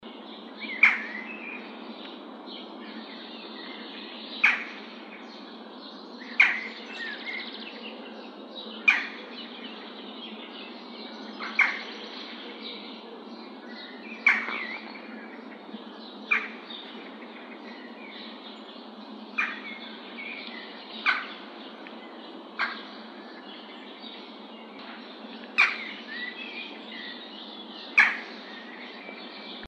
Western Jackdaw (Coloeus monedula)
Life Stage: Adult
Country: England
Condition: Wild
Certainty: Photographed, Recorded vocal